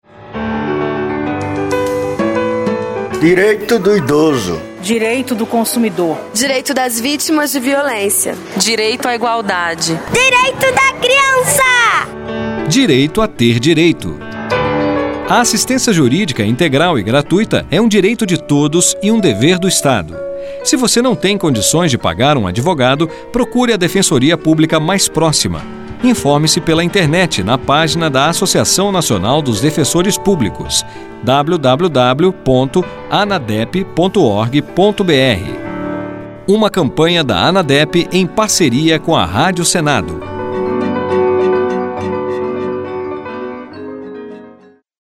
SPOTS: